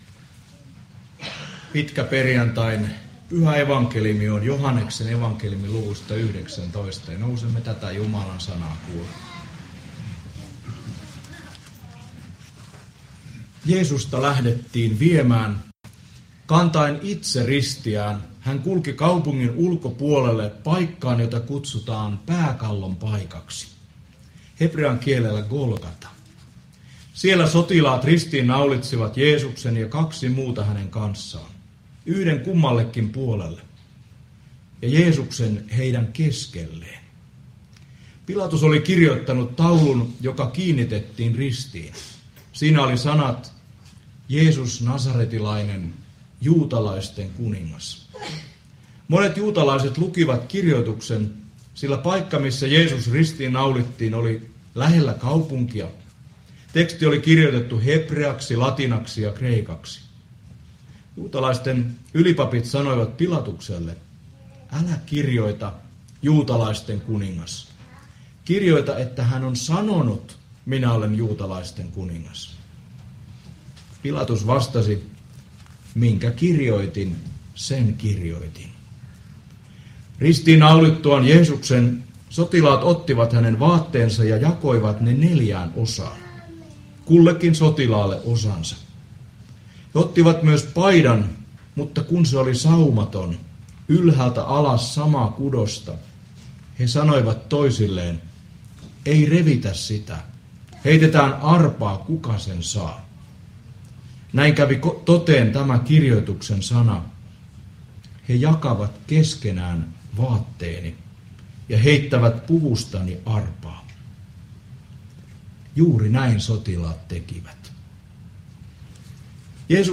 saarna Porissa pitkäperjantaina Tekstinä Joh. 19:16–30